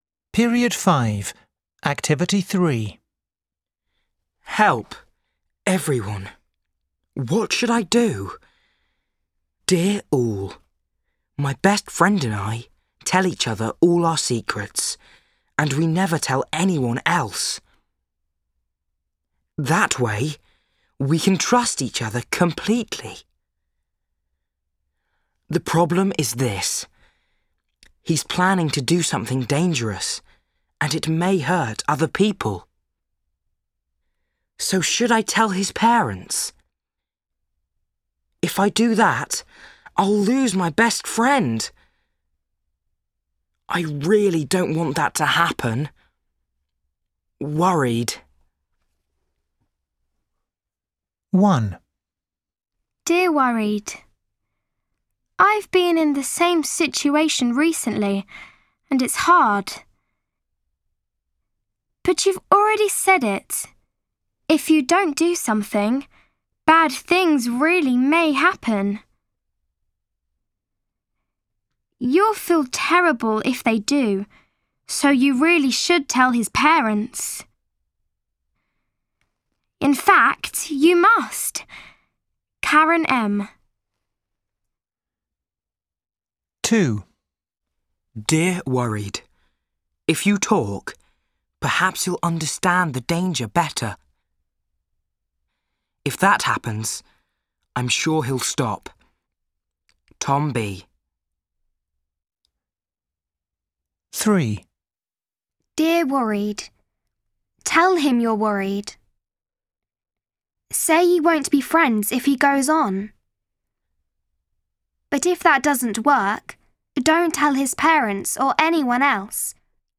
دروس الاستماع